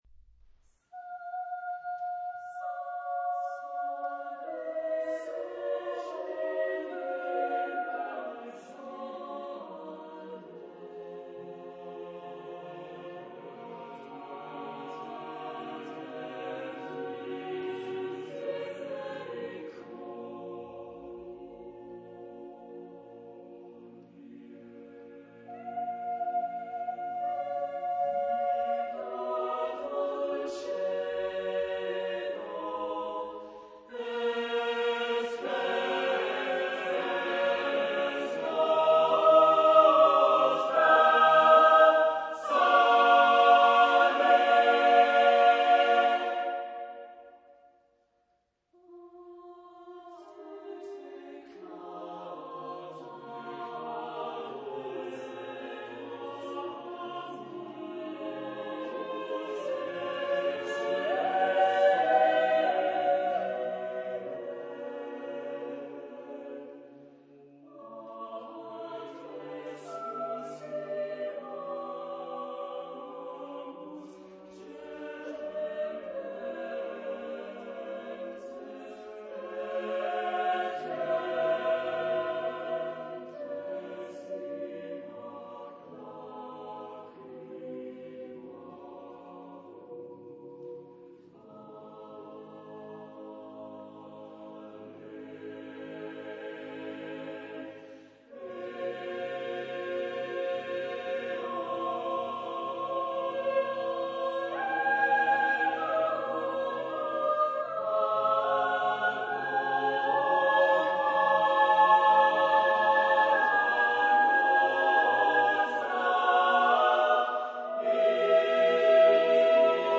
Epoque : 20ème s.
Type de choeur : SATB  (4 voix mixtes )
Réf. discographique : Internationaler Kammerchor Wettbewerb Marktoberdorf